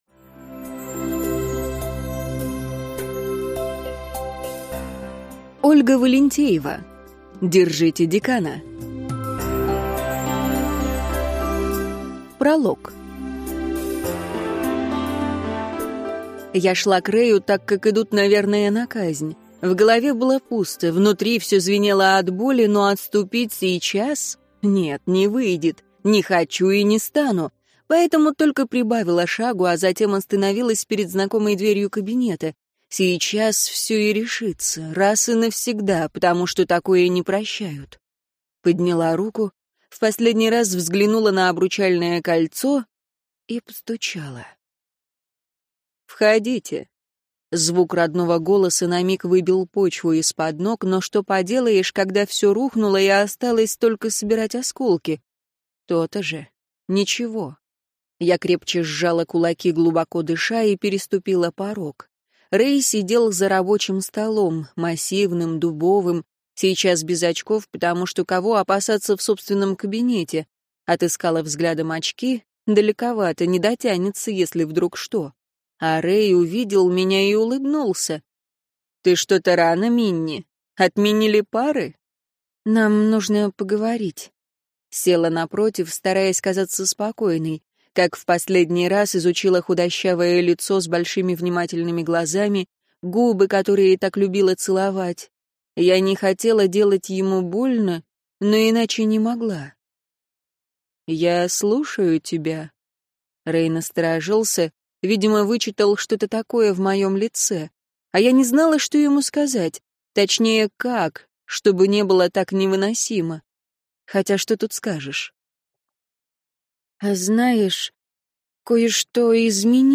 Аудиокнига Держите декана!